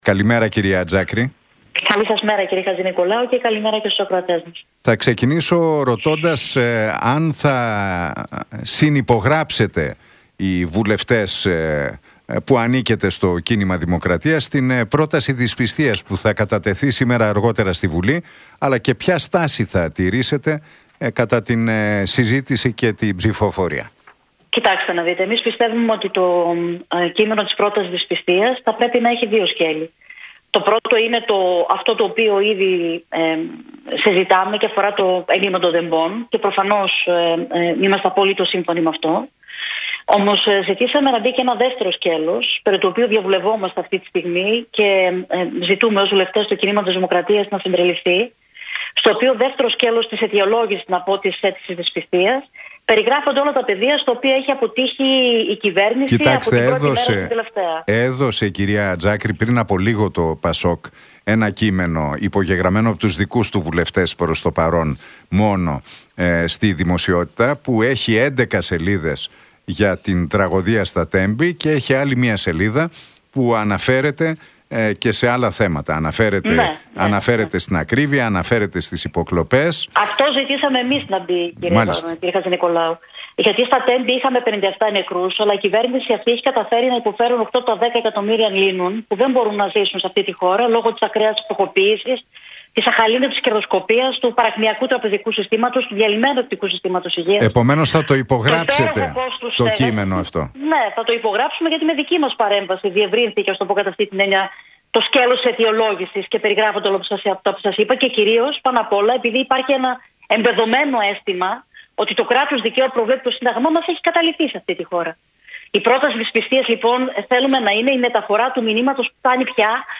Οι ανεξάρτητοι βουλευτές και μέλη του Κινήματος Δημοκρατίας θα υπογράψουν την πρόταση δυσπιστίας κατά της κυβέρνησης που καταθέτουν ΠΑΣΟΚ, ΣΥΡΙΖΑ, Νέα Αριστερά και Πλεύση Ελευθερίας σύμφωνα με όσα είπε η Θεοδώρα Τζάκρη στον Νίκο Χατζηνικολάου από την συχνότητα του Realfm 97,8.